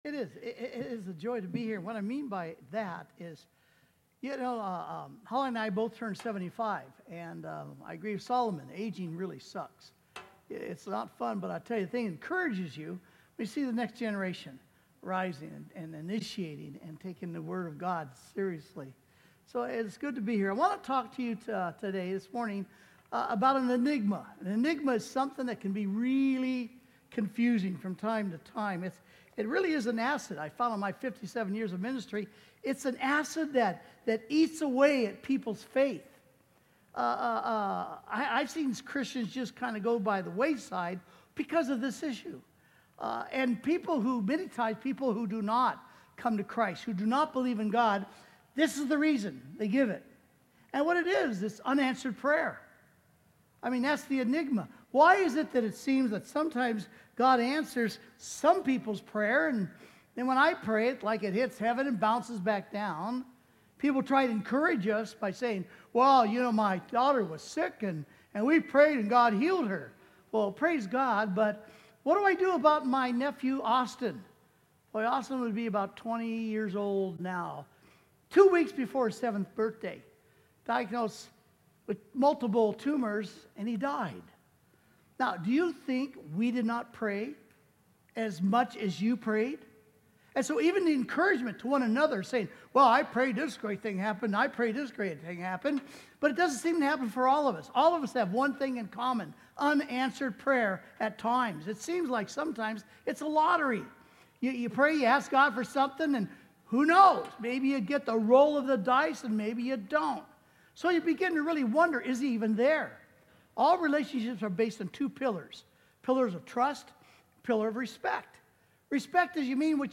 Sermon: When Your Prayers Go Unanswered (4/6/2025)